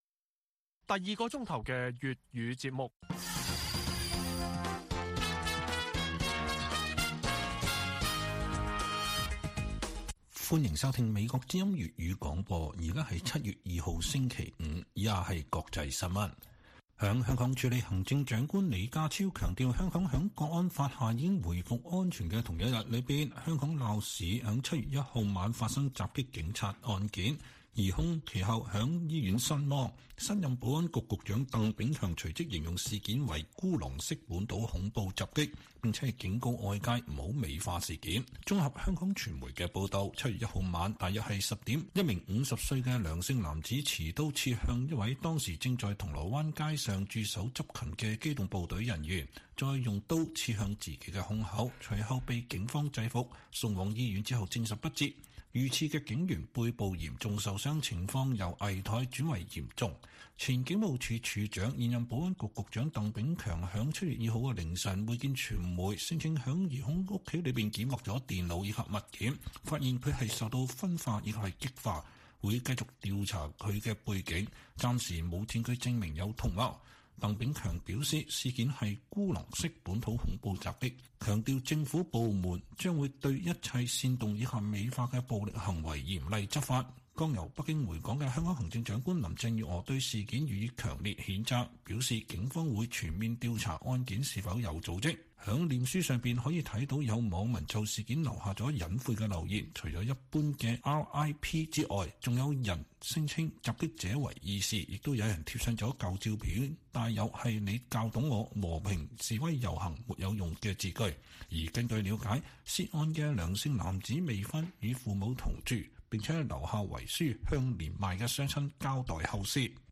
粵語新聞 晚上10-11點 在美港人反思97後香港變化